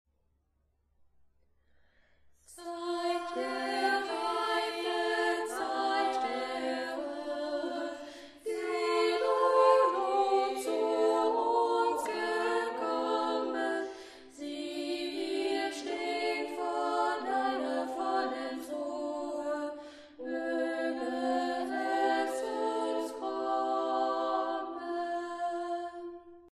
Dieses Lied wurde vom Sextett der Freien Waldorfschule Halle eingesungen.